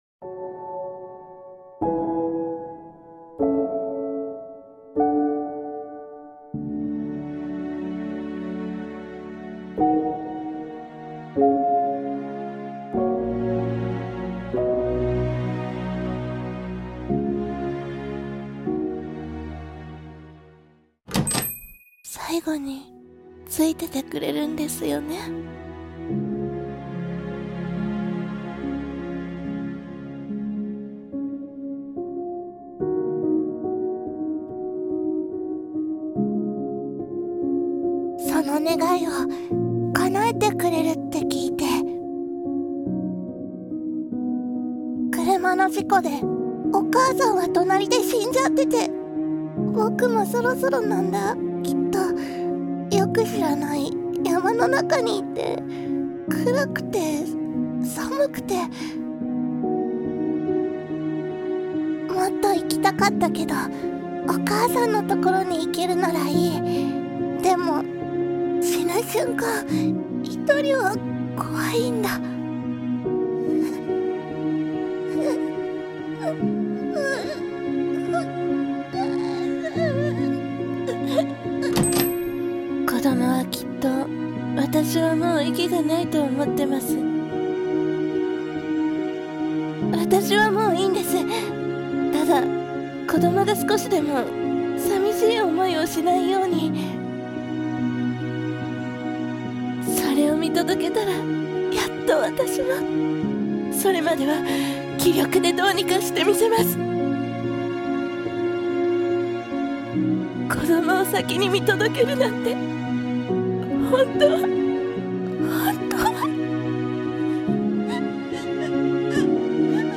映画 看取り屋 【映画予告風 3人声劇台本】